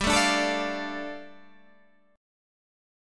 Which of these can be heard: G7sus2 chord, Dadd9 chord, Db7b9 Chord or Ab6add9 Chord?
G7sus2 chord